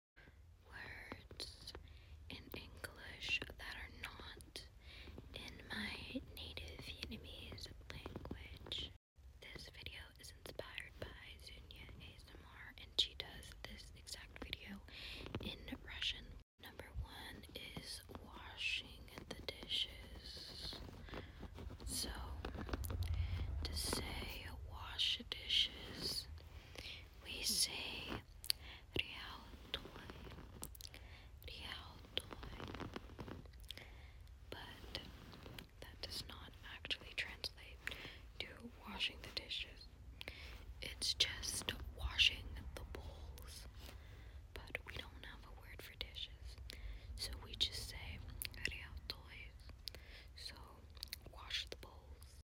💌 — this was a draft from almost 6 months ago! i never finished it but im watching back my drafts and i actually love the plain whispering in this Words In English That Are Sound Effects Free Download.